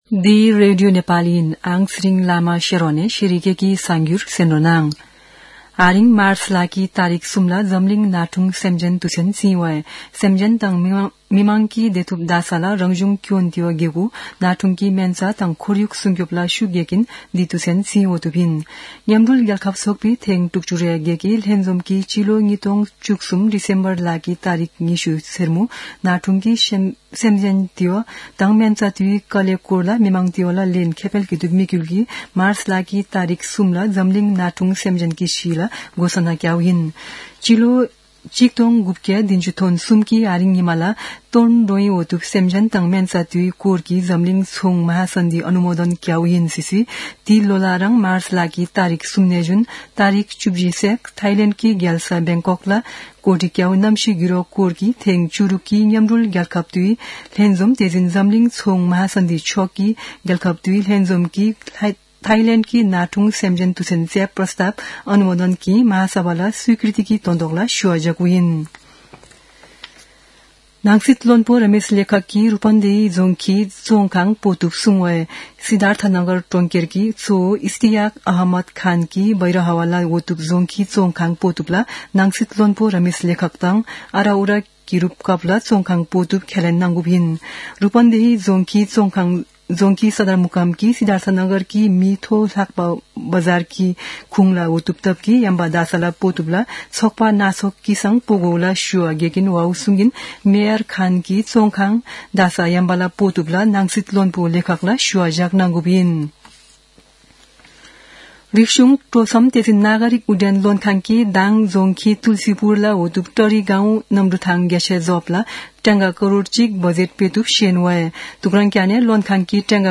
शेर्पा भाषाको समाचार : २० फागुन , २०८१